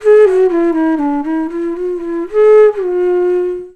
FLUTE-B07 -R.wav